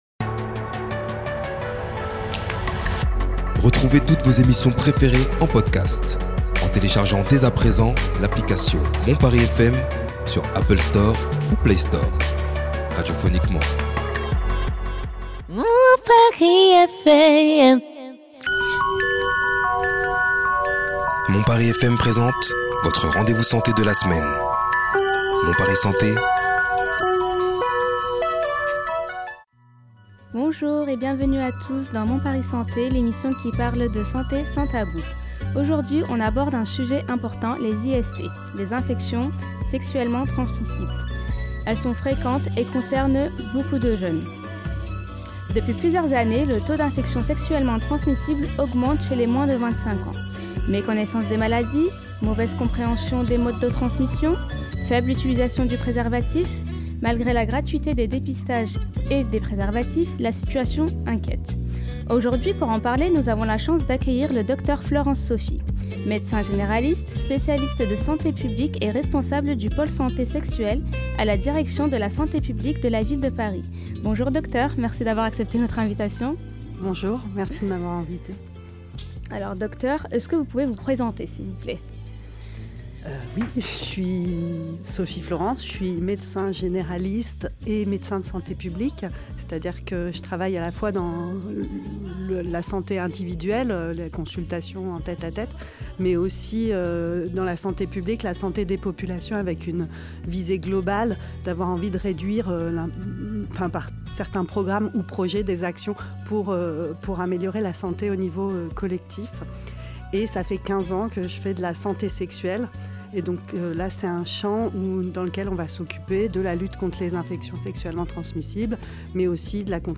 Avec question desauditeurs